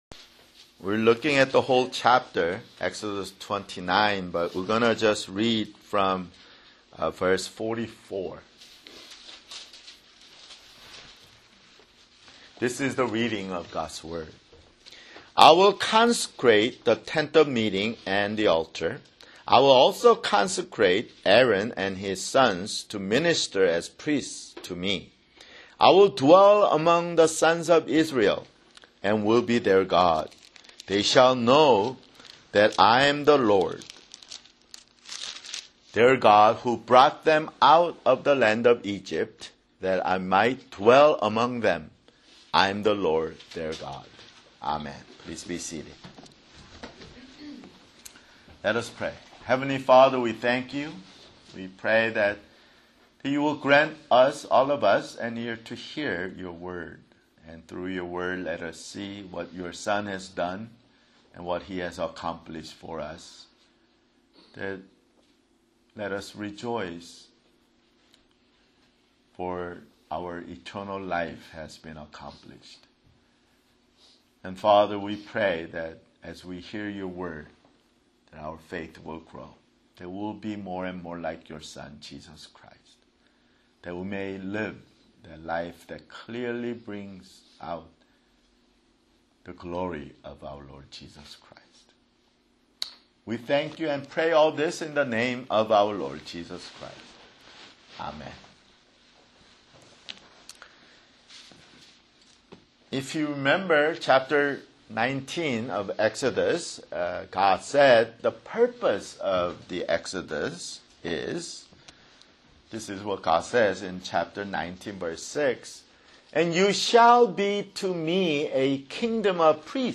[Sermon] Exodus (84)